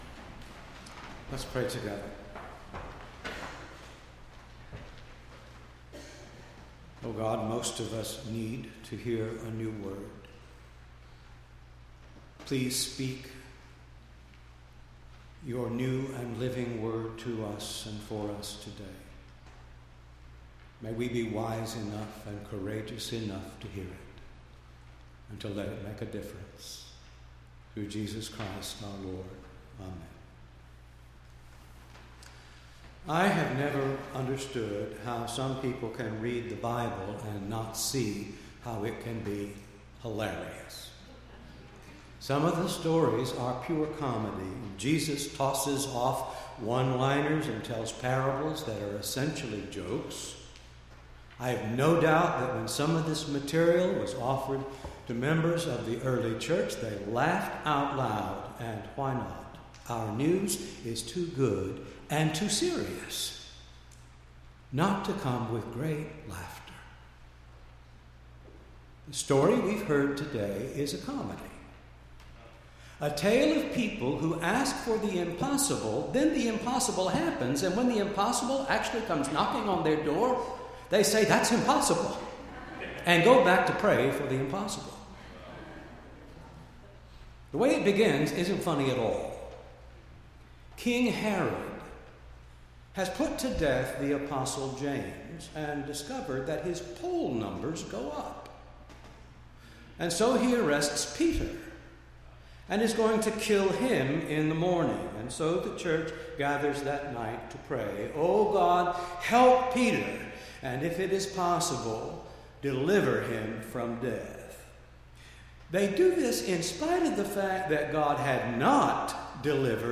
8-7-16-sermon.mp3